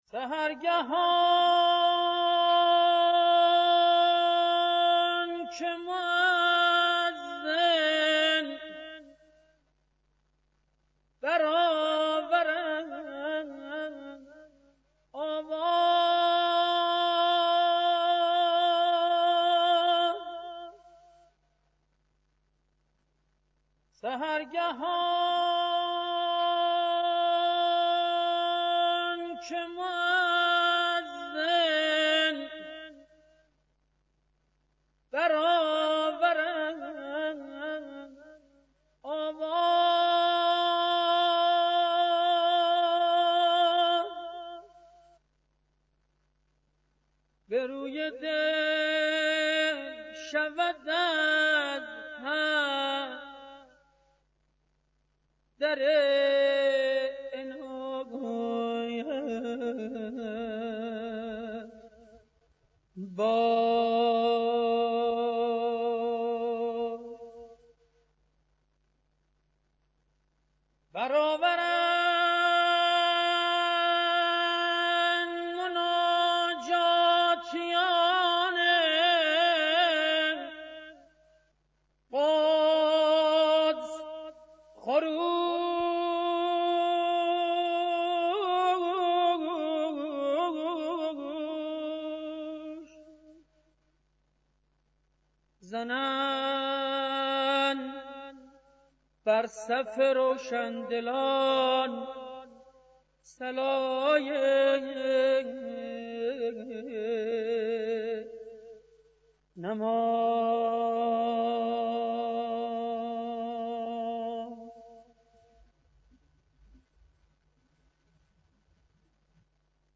خواننده و ردیف‌دان موسیقی ایرانی
مناجات